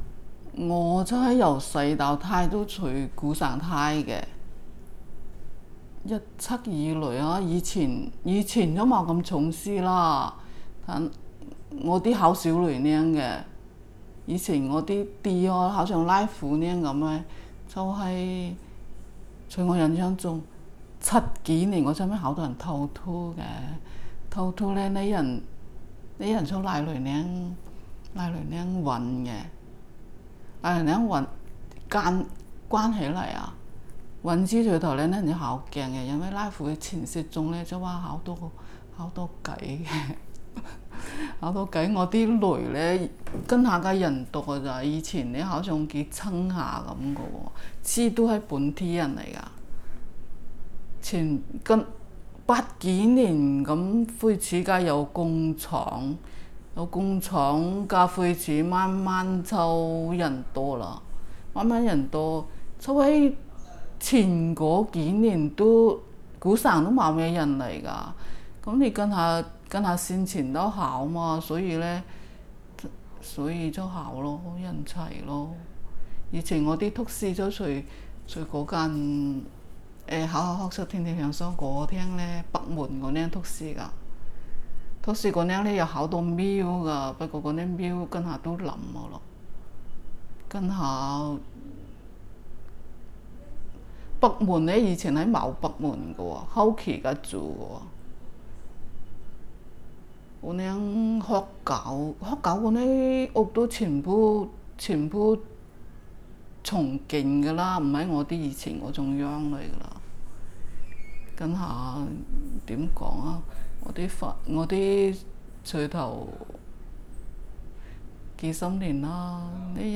Female, 48
digital wav file recorded at 44.1 kHz/16 bit on Zoom H2 solid state recorder
Dapeng, Shenzhen, Guangdong Province, China
Dapeng dialect in Shenzhen, China